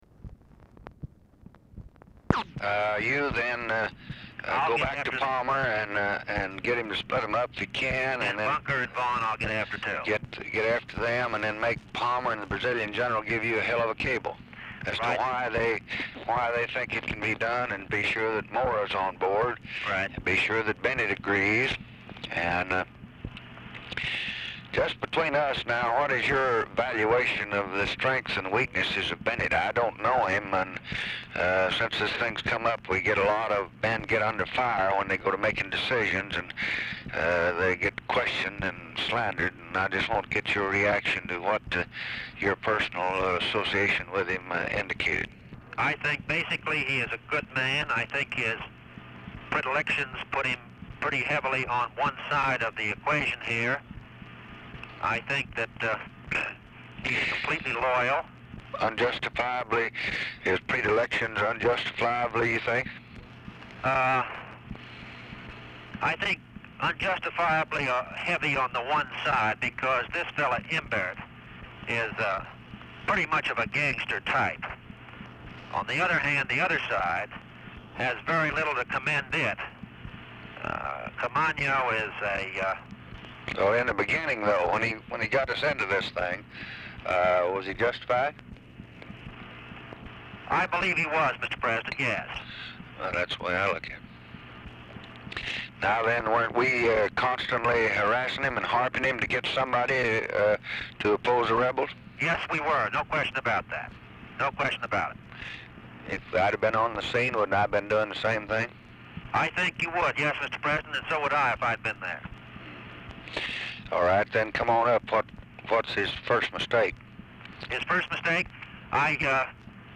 Telephone conversation # 7846, sound recording, LBJ and CYRUS VANCE, 5/31/1965, 8:44AM | Discover LBJ
Format Dictation belt
Location Of Speaker 1 LBJ Ranch, near Stonewall, Texas
Specific Item Type Telephone conversation Subject Business Communist Countries Defense Diplomacy Latin America Religion